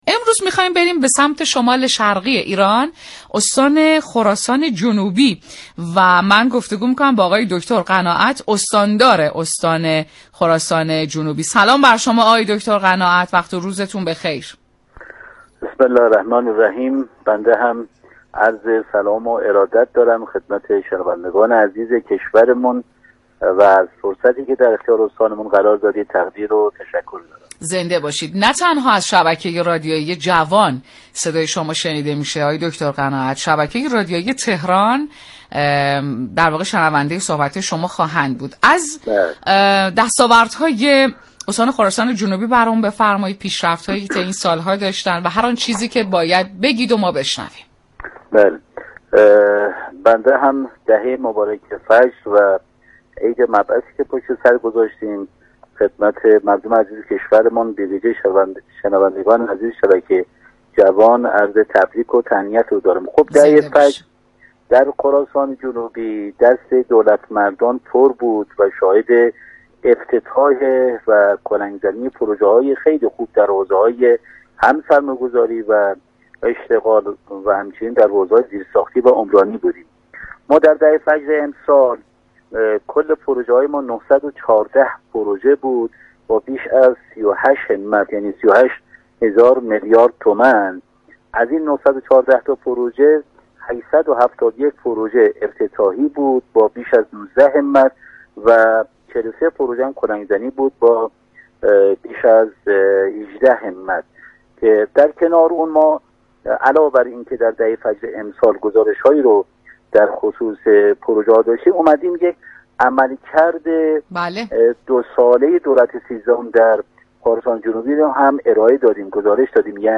به گزارش پایگاه اطلاع رسانی رادیو تهران، جواد قناعت استاندار استان خراسان جنوبی در گفت و گو با «در مسیر انقلاب» ضمن تبریك ایام الله دهه فجر اظهار داشت: در استان خراسان جنوبی همزمان با ایام دهه فجر، شاهد افتتاح و كلنگ‌زنی پروژه‌های متعددی با اعتباری معادل 38 همت (هزار میلیارد تومان) در حوزه‌های عمرانی، سرمایه‌گذاری، اشتغال و زیرساختی بودیم.